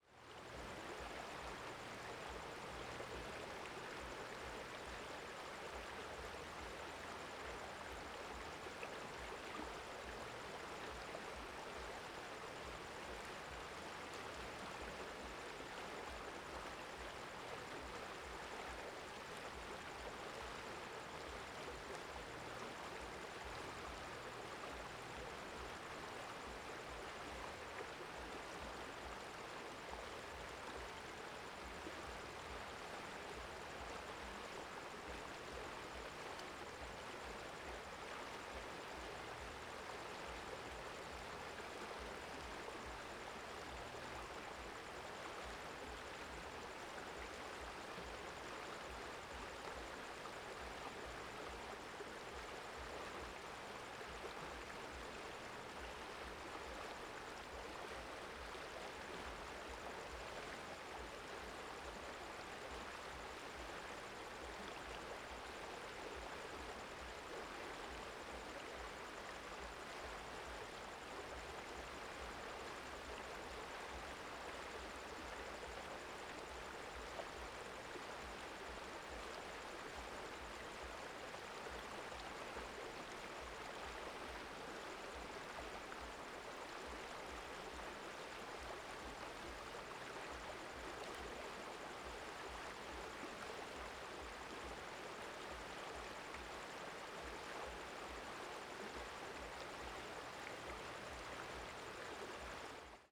CSC-01-089-OL- Rio Galheiros correnteza.wav